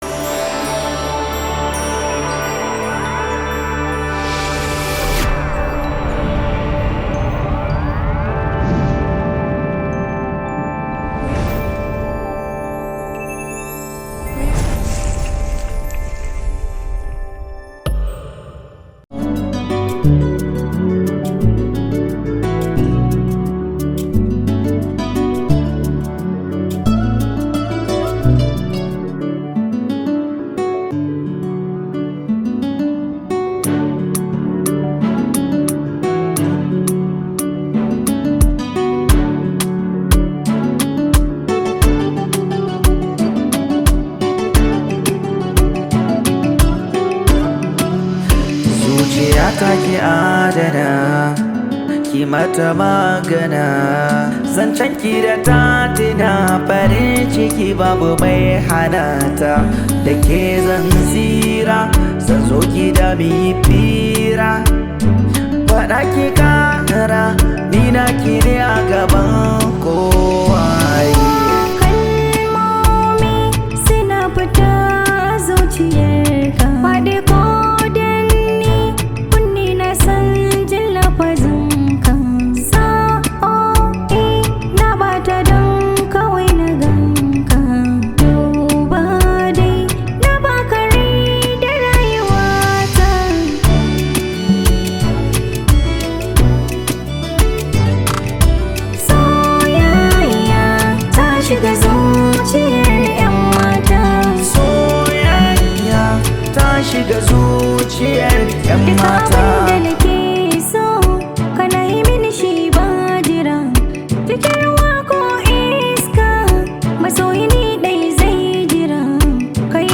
Prolific and highly talented hausa Love song maker
an Arewa rooted song